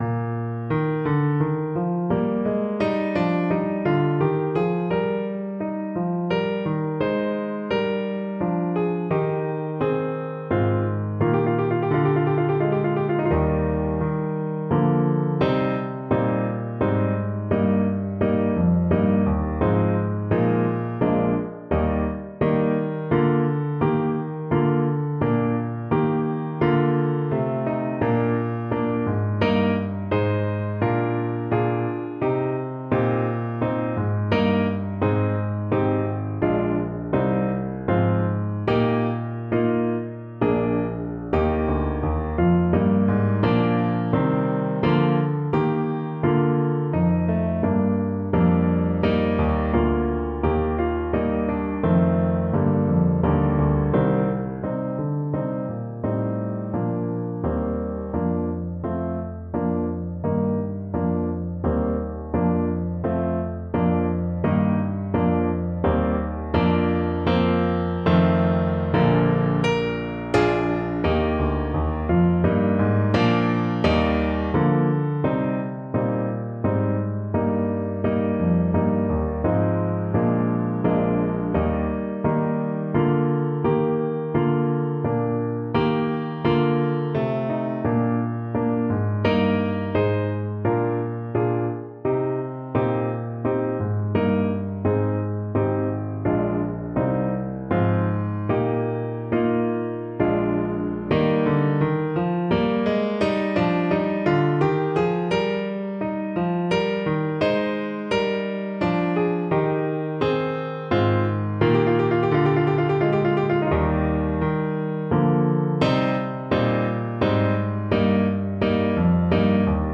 Canzonetta Alto Saxophone version
3/4 (View more 3/4 Music)
Allegro giusto =126 (View more music marked Allegro)
Classical (View more Classical Saxophone Music)